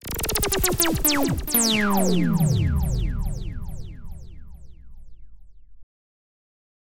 描述：这是一种酸性合成物的下降
Tag: 140 bpm Acid Loops Fx Loops 1.15 MB wav Key : Unknown